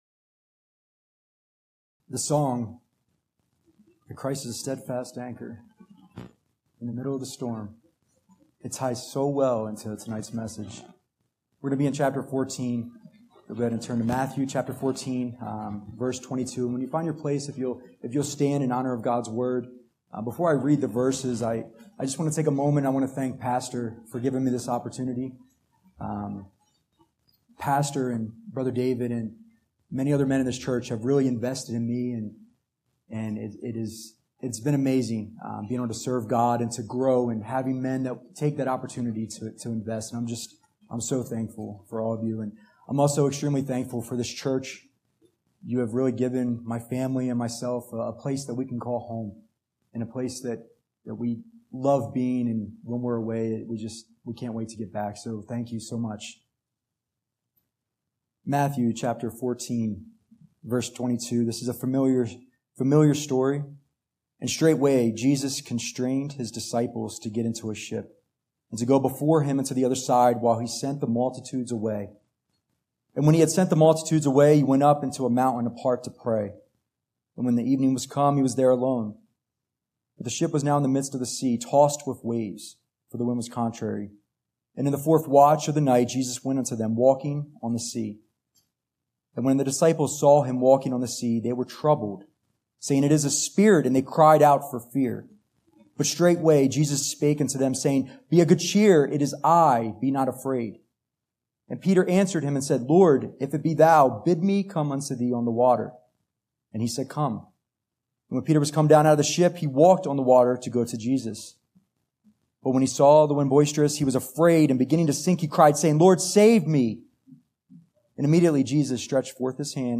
" Guest & Staff Preachers " Guest & Staff Preachers at Bethany Baptist Church Scripture References: Matthew 14:22-33